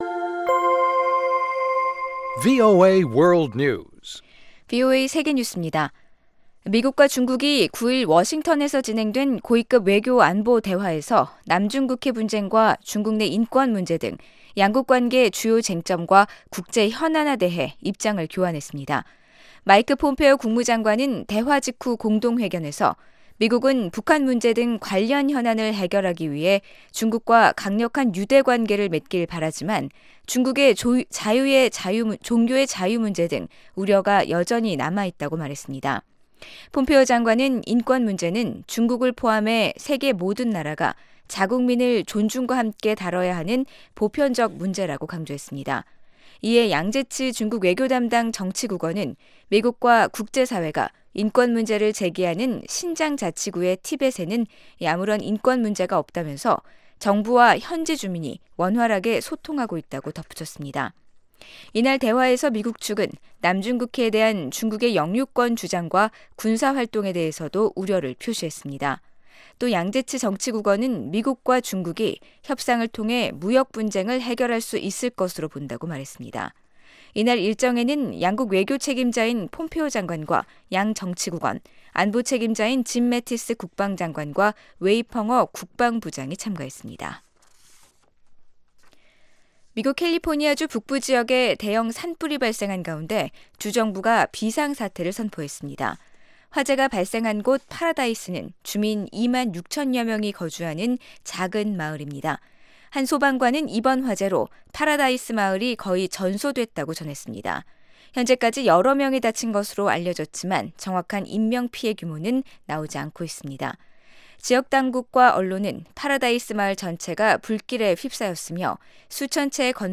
VOA 한국어 아침 뉴스 프로그램 '워싱턴 뉴스 광장' 2018년 11월 10일 방송입니다. 러시아가 유엔 안보리에서 대북 금융제재 완화를 요구한데 대해 미국은 거부 의사를 분명히 했습니다. 유엔 안보리 대북제재위원회가 유엔아동기금에 인도주의 물품의 대북 반입을 허가했습니다. 미국 하원 다수당이된 민주당 의원들이 앞으로 북한 청문회를 요구할 것이라고 외교전문지가 전망했습니다.